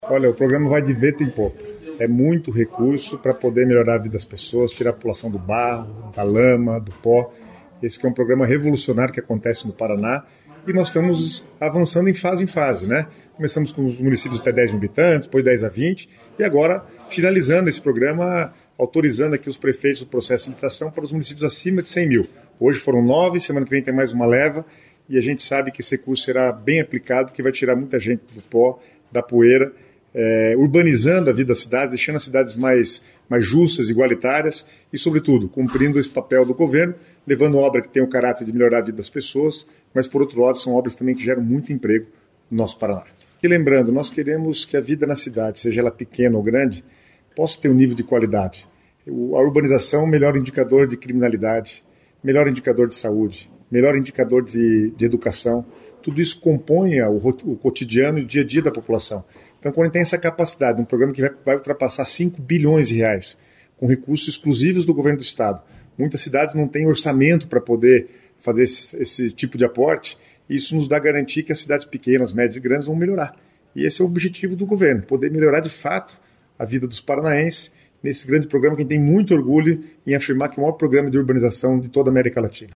Sonora do secretário de Estado das Cidades, Guto Silva, sobre a liberação de recursos pelo Asfalto Novo, Vida Nova para 5 municípios do interior e Litoral